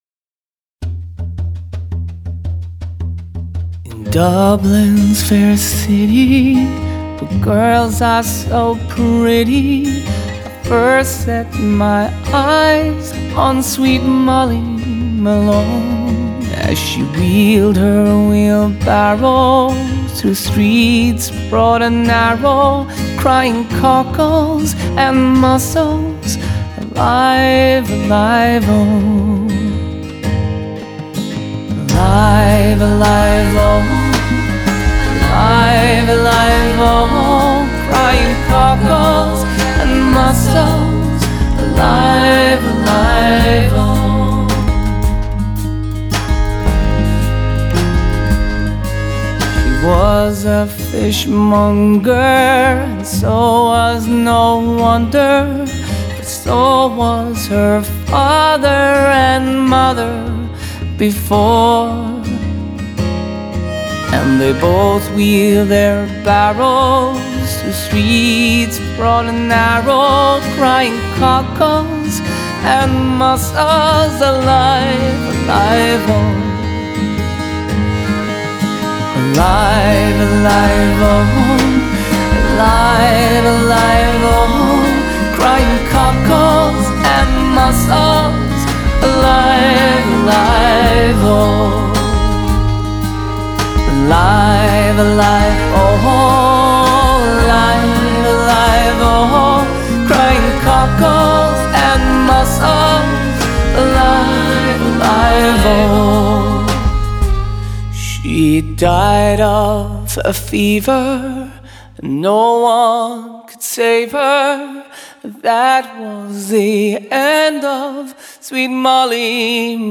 Genre: Folk/Country/Pop